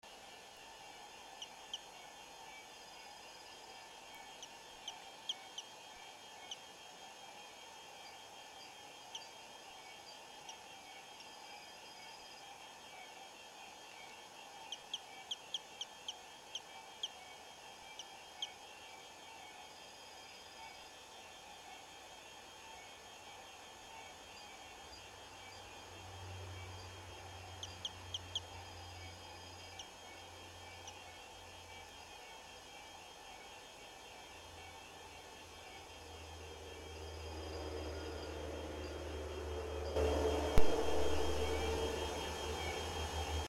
Dendrophryniscus berthalutzae
Local: Serra do Mar - Jaraguá do Sul, Santa Catarina